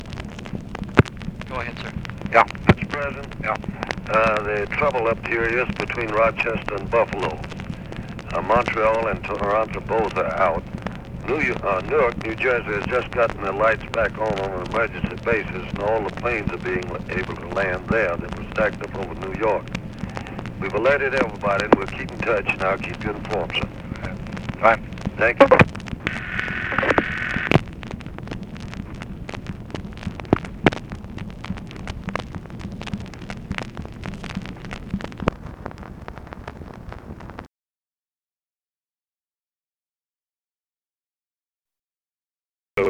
Conversation with BUFORD ELLINGTON, November 9, 1965
Secret White House Tapes